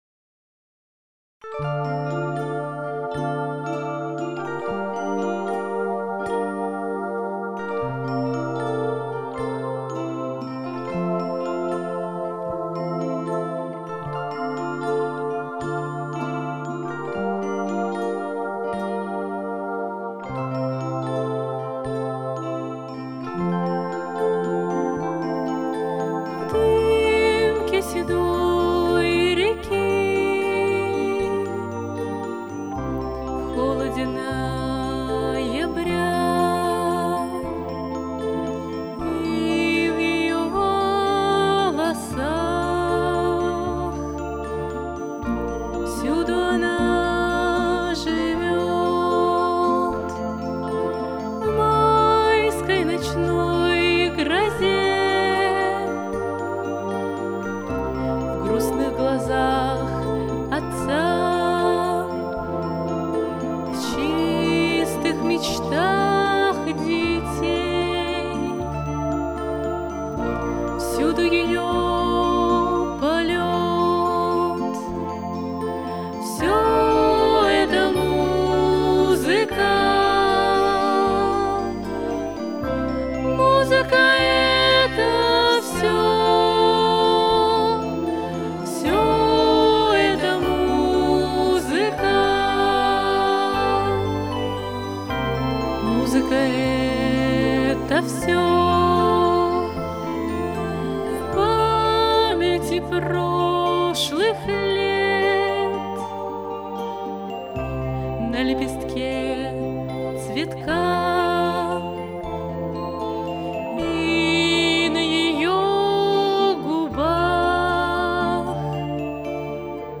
Записано в студии